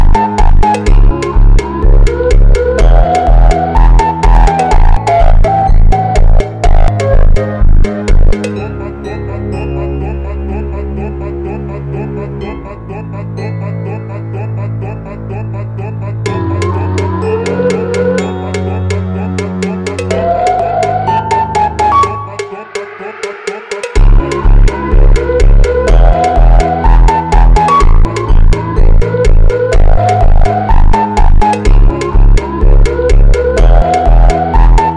Модульные синтезаторы и глитчи трека
Жанр: Электроника